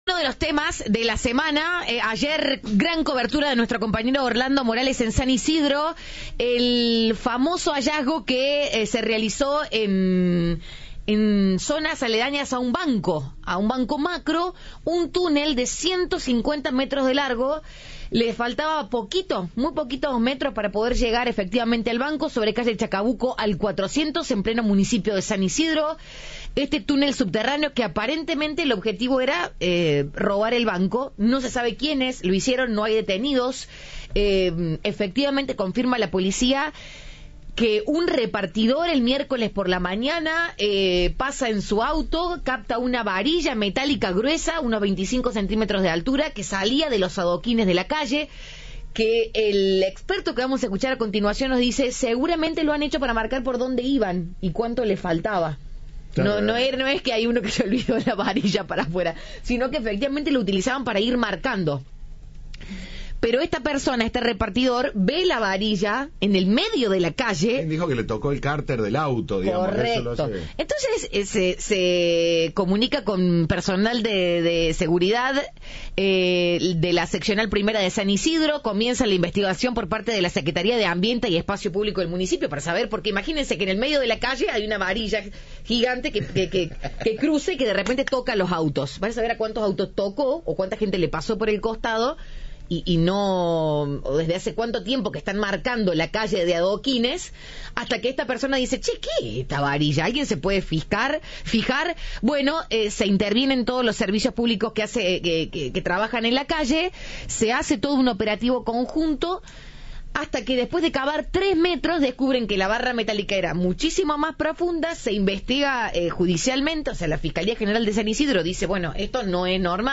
Entrevista de "Siempre Juntos".